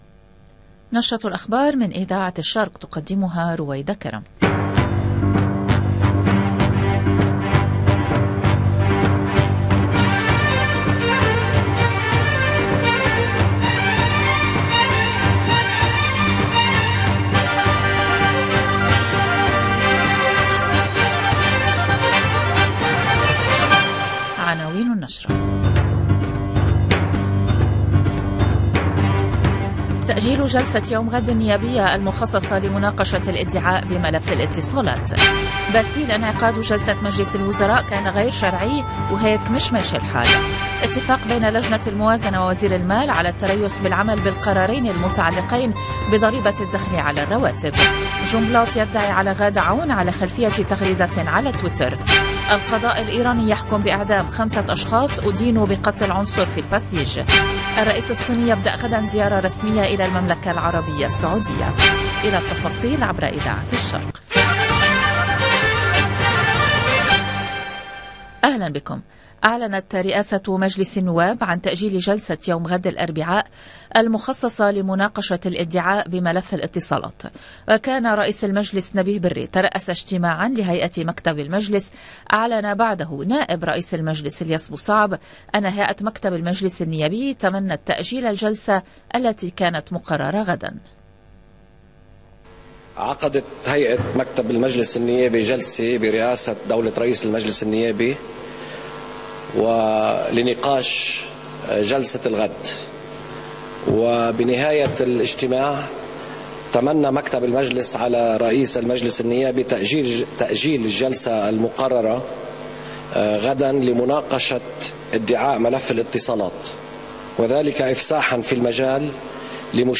EDITION DU JOURNAL DU LIBAN DU SOIR DU 6/12/2022